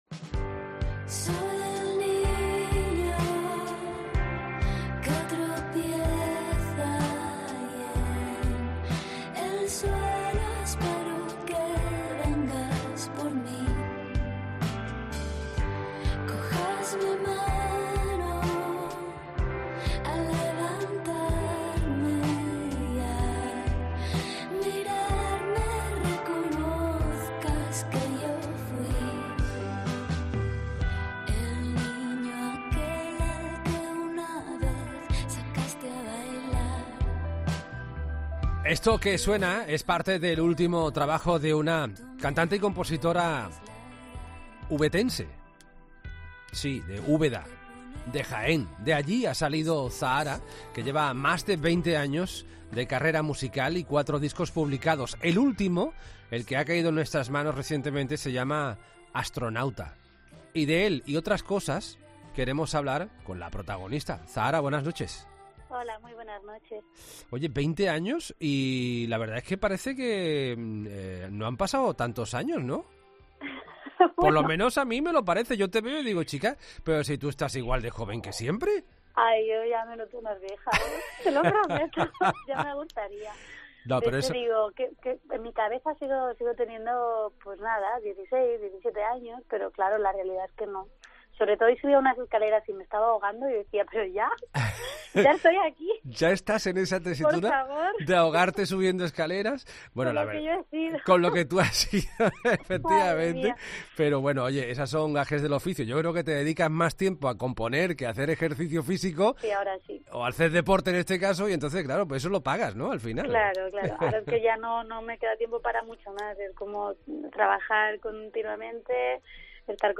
Una de las cantantes de música 'indie' más importantes de España habla en 'La Noche de COPE' de su nuevo disco, su carrera y la música.